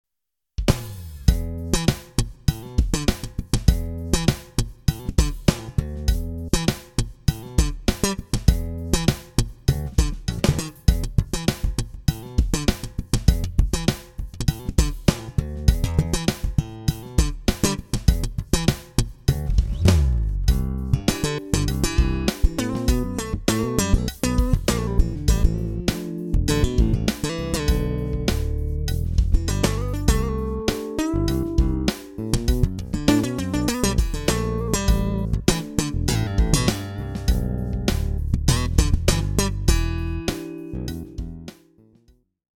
All of the samples were DI'd (direct injected) for sampling.
Slap bass
UBK_demo_slapbass.mp3